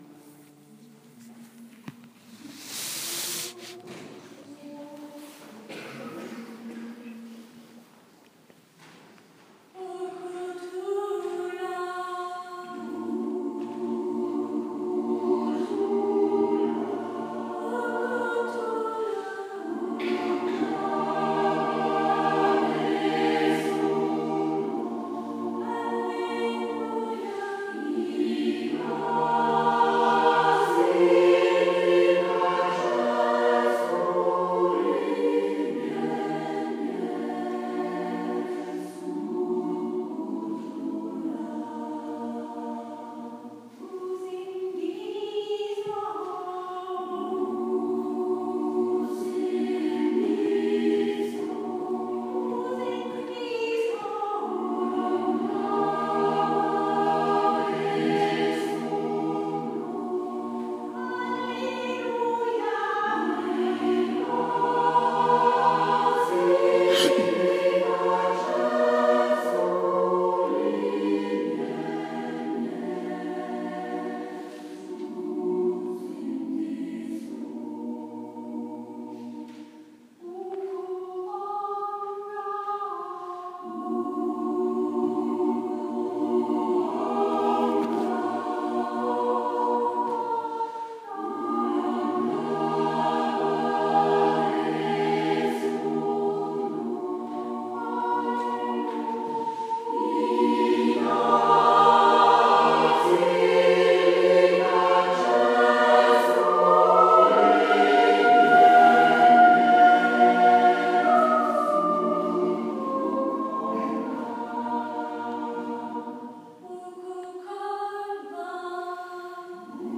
Kirchenchor Sigharting